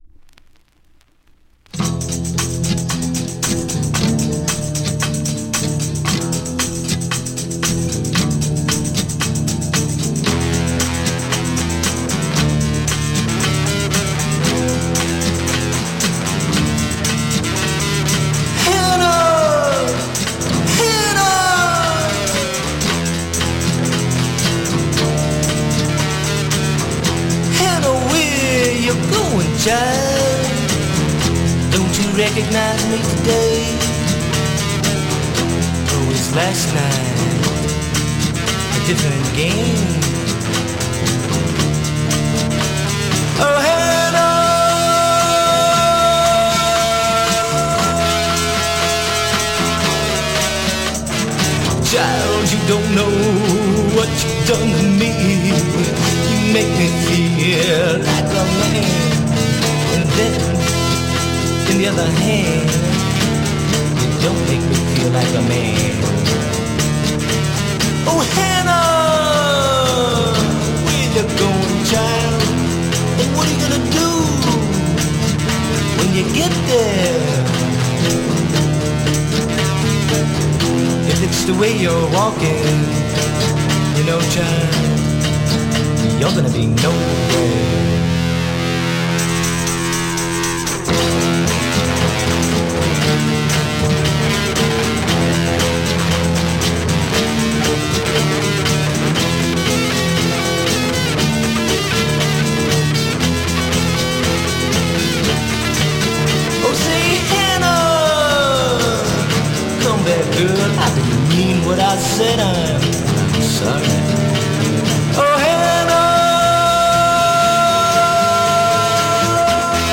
Classic Garage Fuzz out Mod spin!